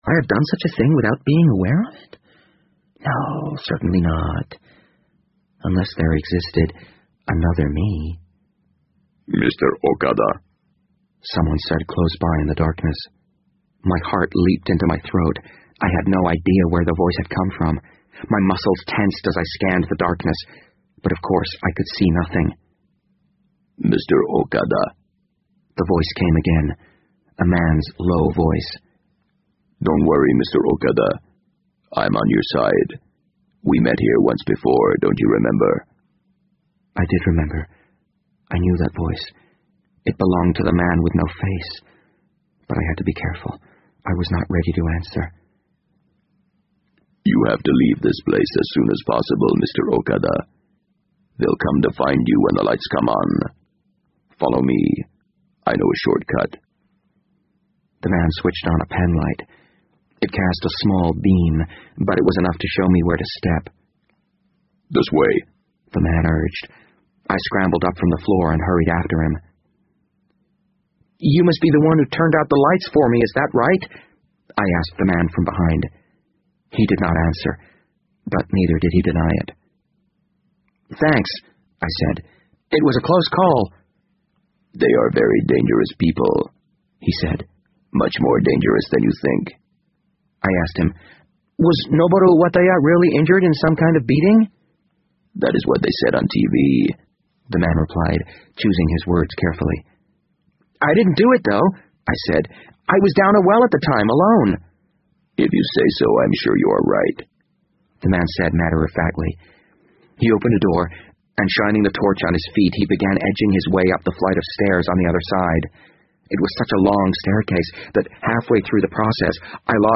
BBC英文广播剧在线听 The Wind Up Bird 015 - 3 听力文件下载—在线英语听力室